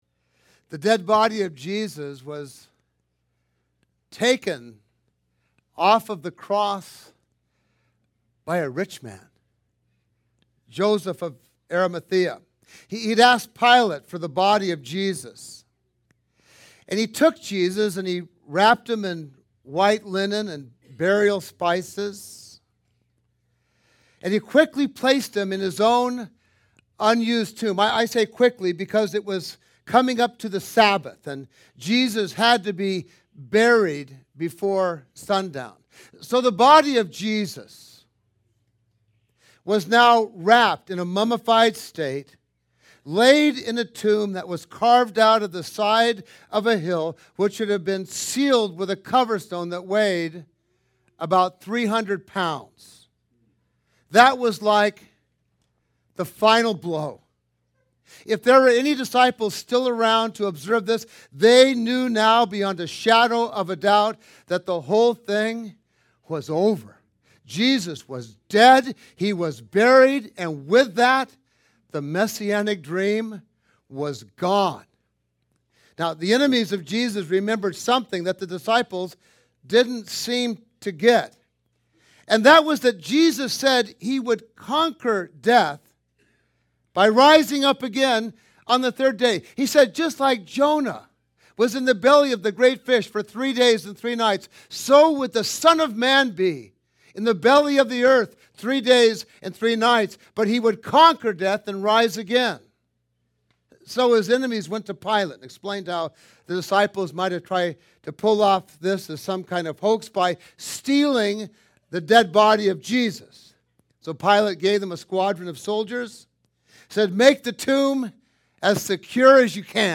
This sermon was for our Easter Sunrise Service. Jesus died and rose from the grave by His own power.